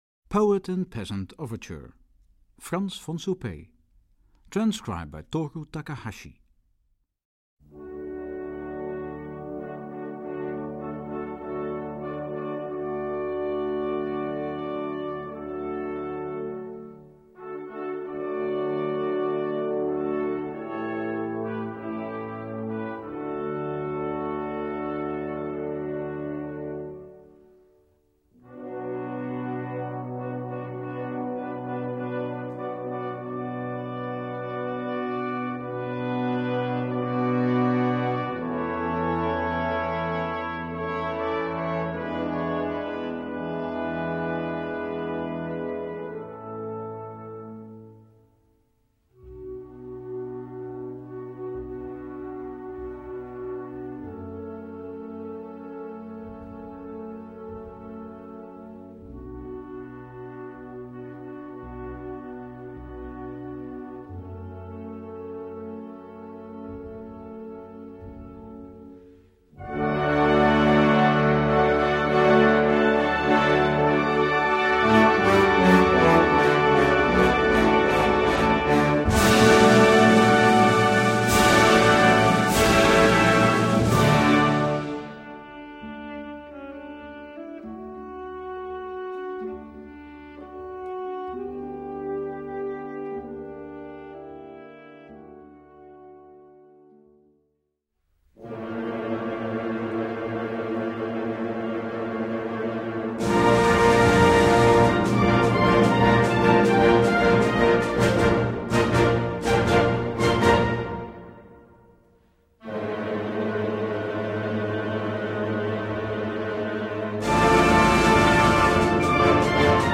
Key : D Major and B-flat Major (original key)